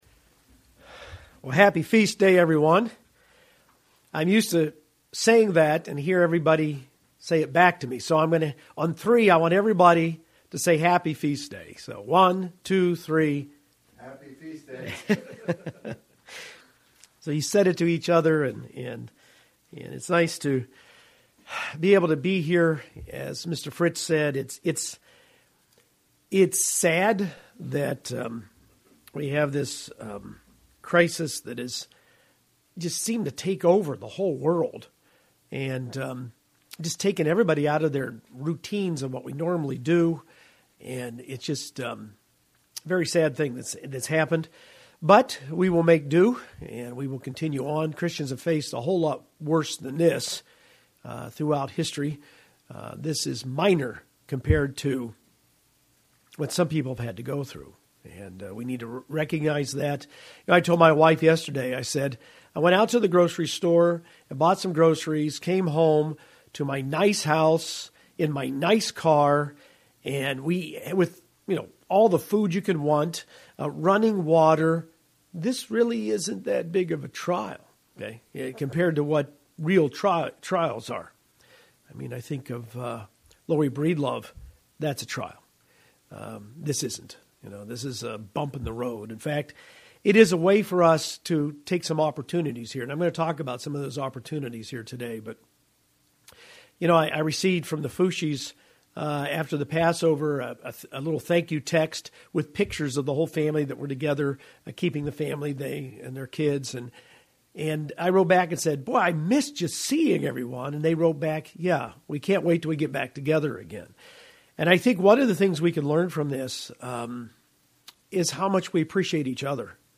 Seven sacrifices we must give to God in appreciation for Jesus’ ultimate sacrifice for us. First Day of Unleavened Bread 2020.